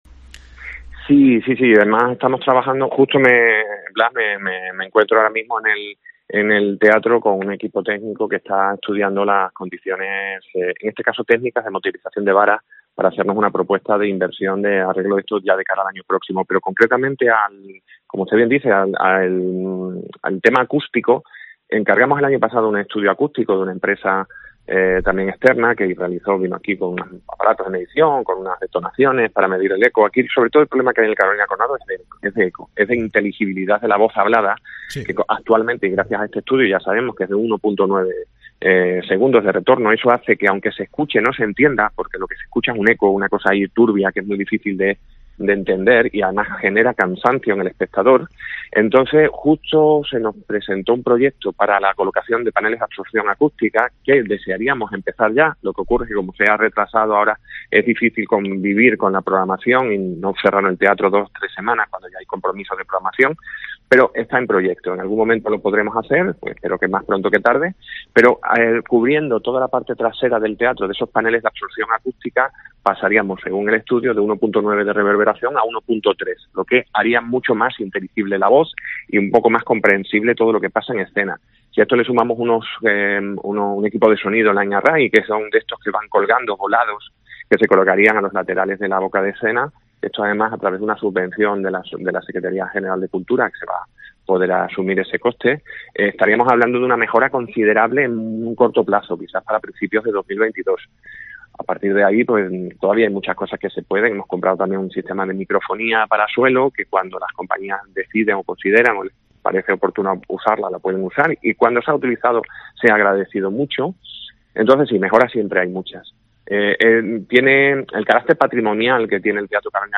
El concejal de Acción y Promoción Cultural, Domingo Cruz, en una entrevista en COPE Almendralejo, ha afirmado que un estudio acústico que se encargó el año pasado reveló que los problemas de sonidos del teatro son producidos por el eco, por la reverberancia.